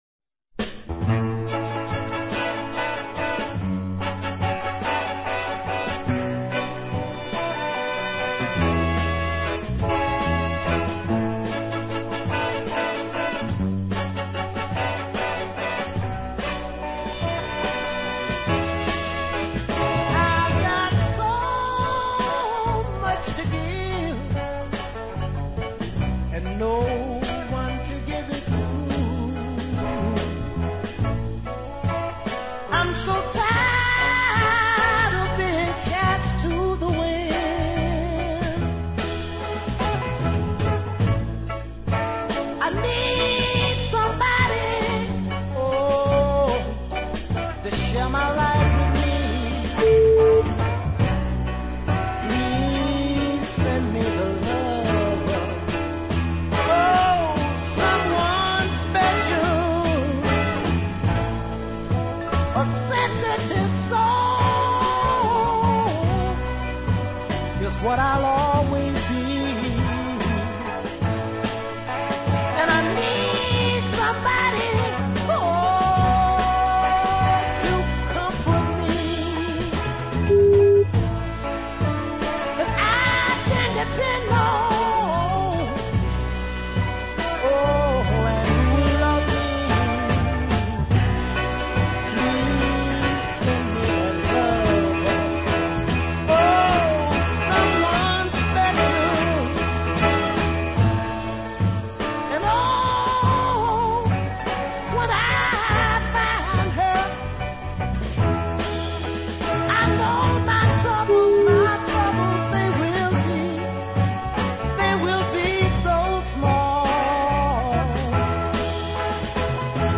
Love those falsetto shrieks too.